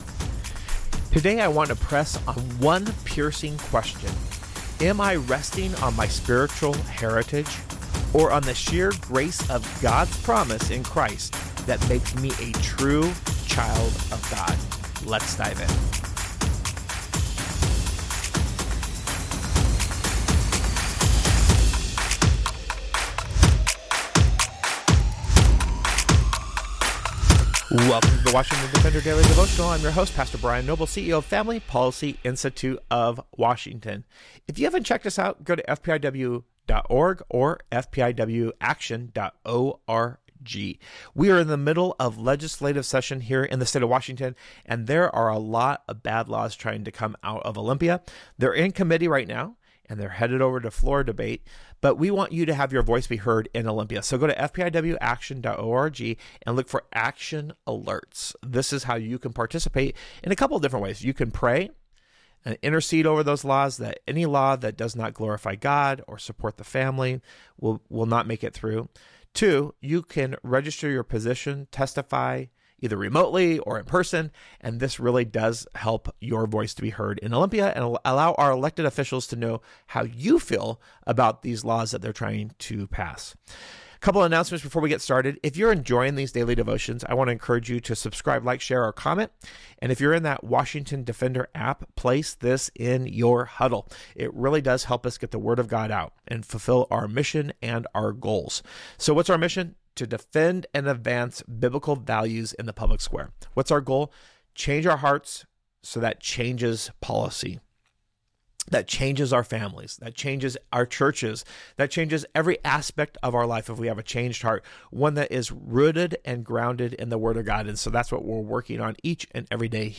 A Devotion for your drive into work: